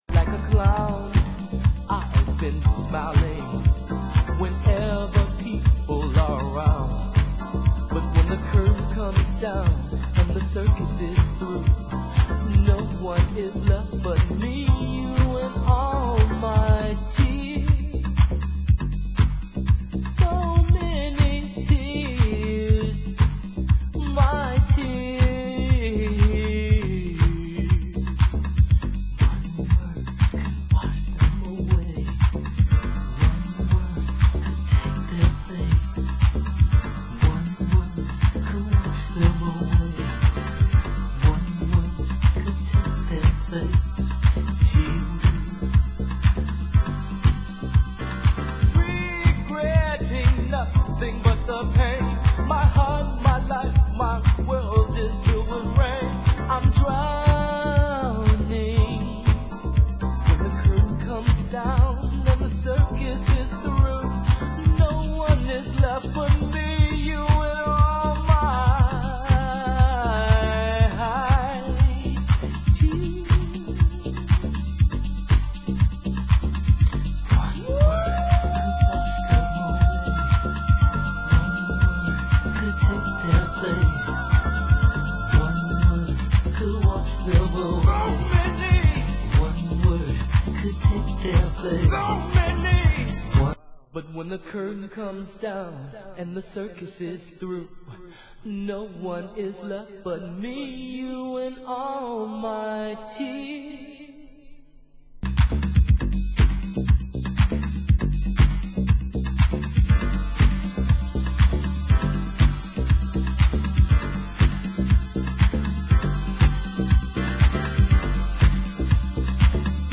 Genre: Warehouse